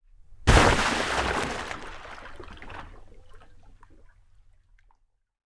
Water_51.wav